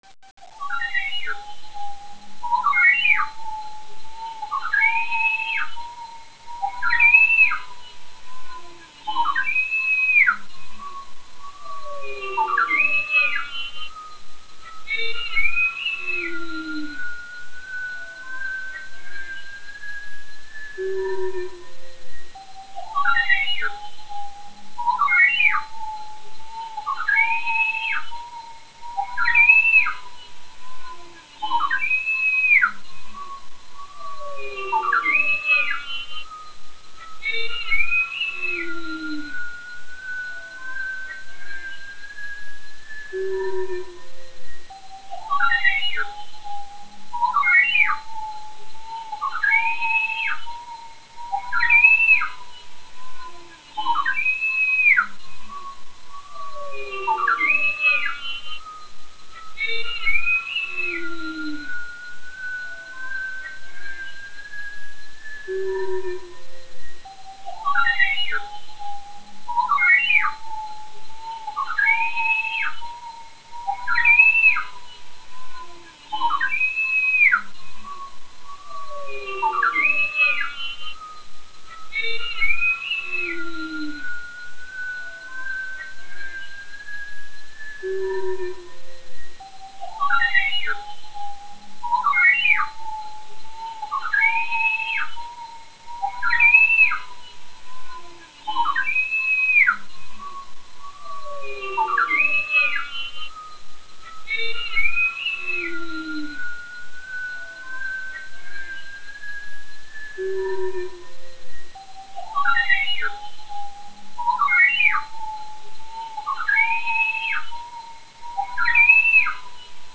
Nom commun : Béluga
Nom latin : Delphinapterus leucas
Beluga_Sifflement_clean_01.wav